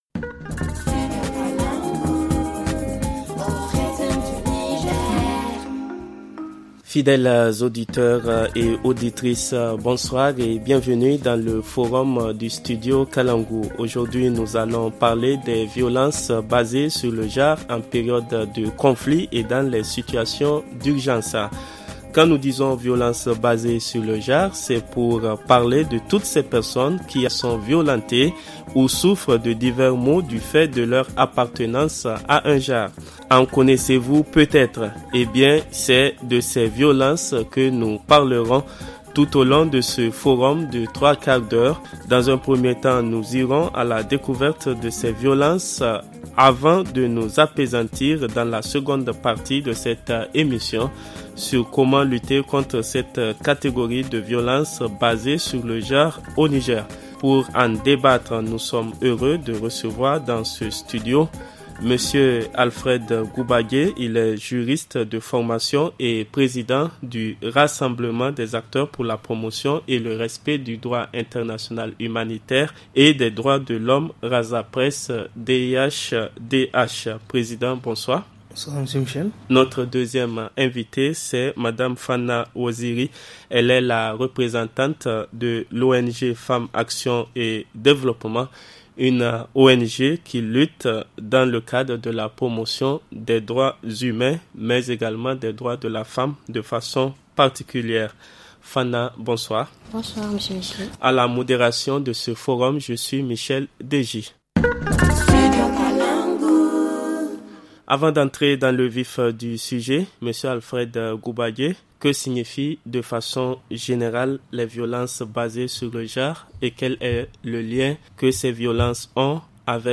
Pour en débattre, nous recevons :